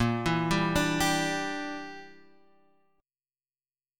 Bb6b5 chord